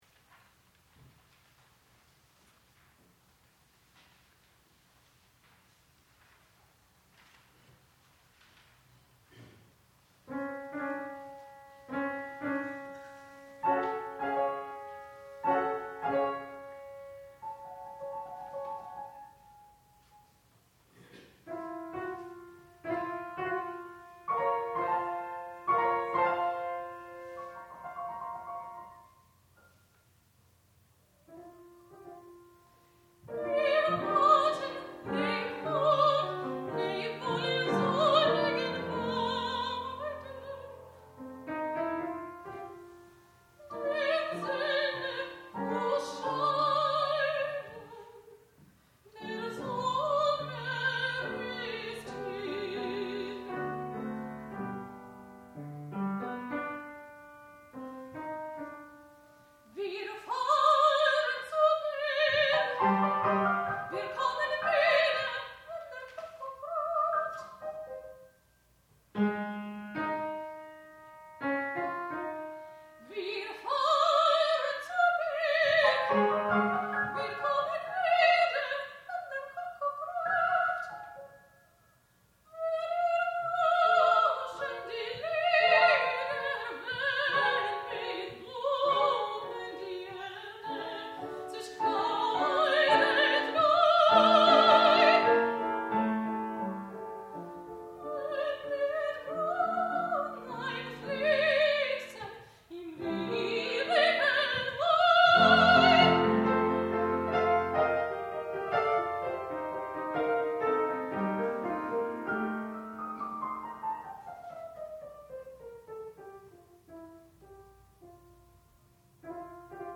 sound recording-musical
classical music
piano
mezzo-soprano
Junior Recital